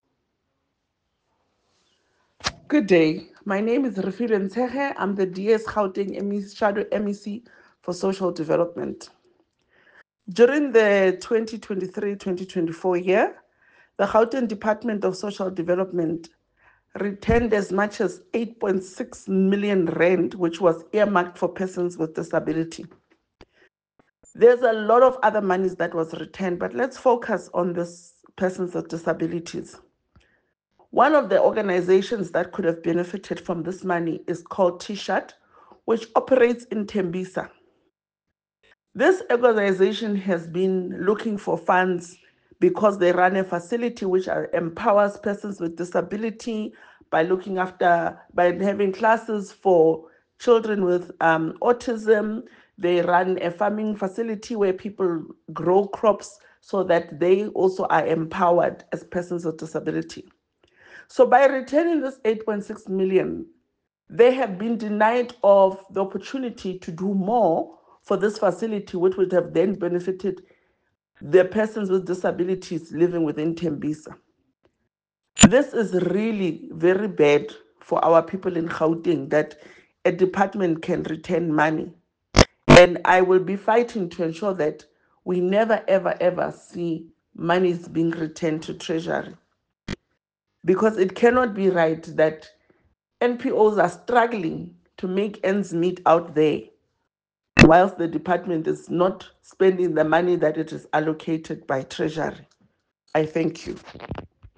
English soundbite by Refiloe Nt’sekhe MPL.